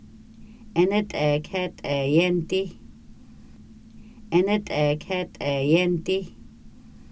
Individual audio recordings of Kaska words and phrases expressing encouragement. This subset of the original Encouragement Deck focuses on perseverance and collaboration.